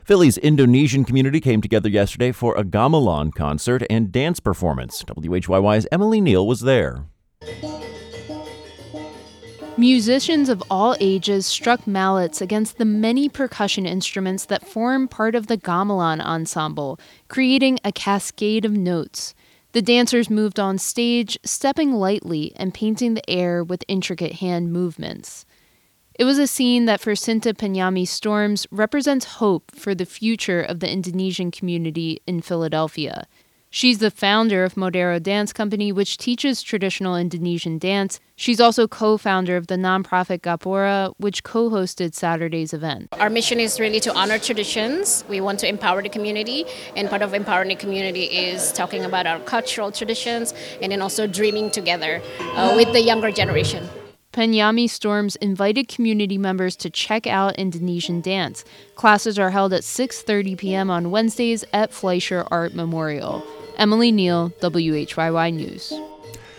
It was told at a story slam, where true-life stories are presented around a theme, no notes allowed. The theme that night was “girl power.”